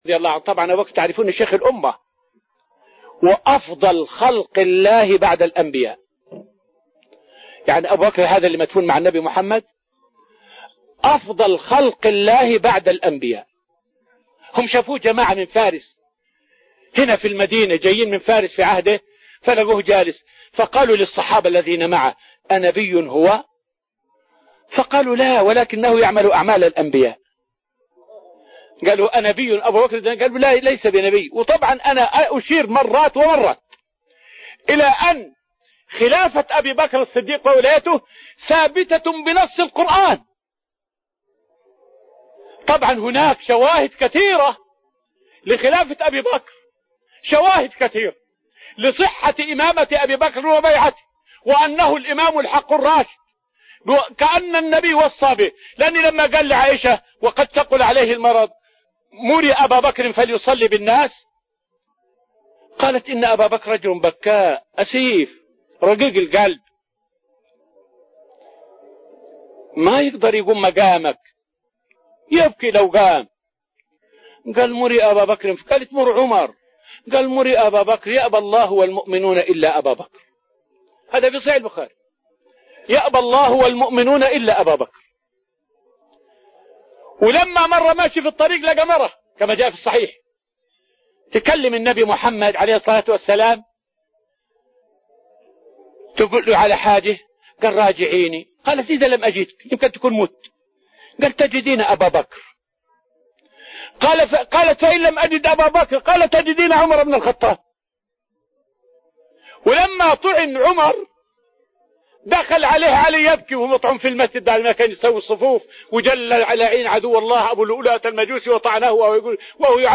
فائدة من الدرس الأول من دروس تفسير سورة الأنفال والتي ألقيت في رحاب المسجد النبوي حول دلائل خلافة أبي بكر من القرآن والسنة.